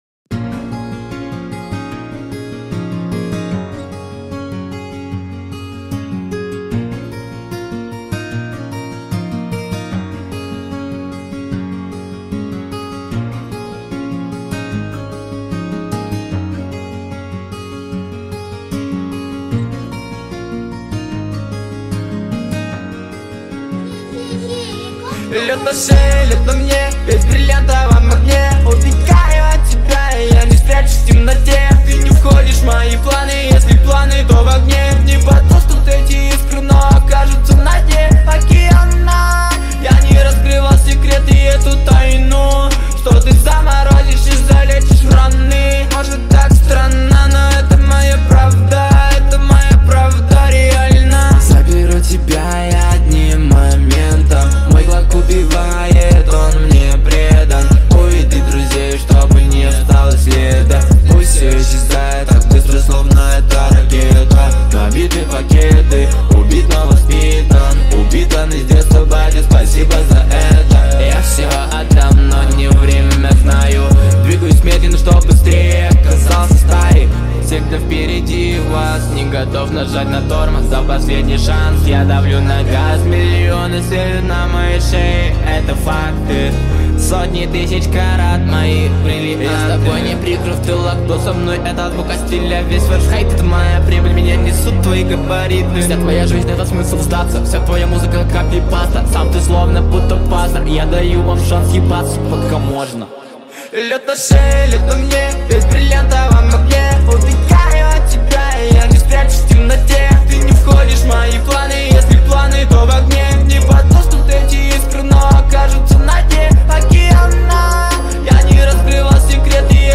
это энергичный трек в жанре хип-хоп